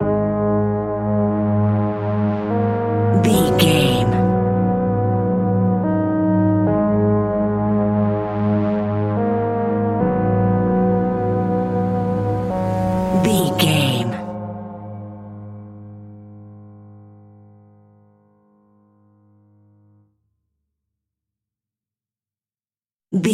Aeolian/Minor
A♭
ominous
haunting
eerie
piano
synthesizer
horror music
Horror Pads
horror piano
Horror Synths